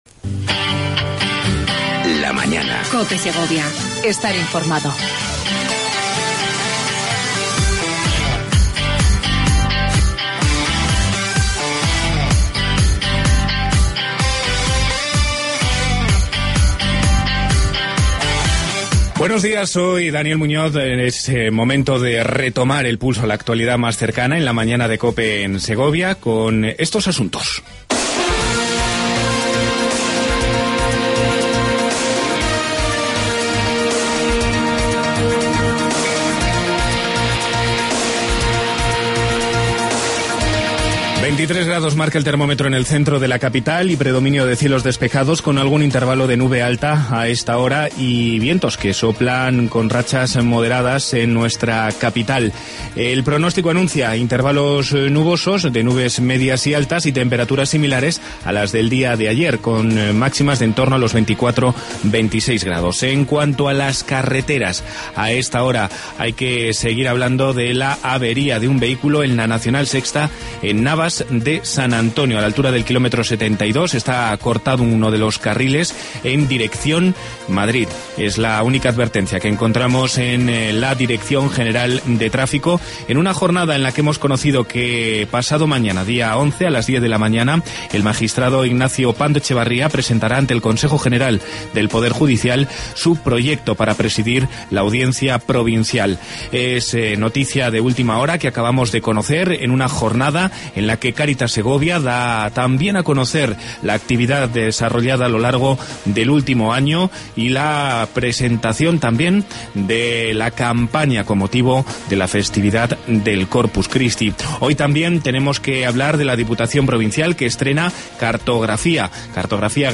AUDIO: Entrevista con Francisco Vazquez, presidente de la Diputación de Segovia.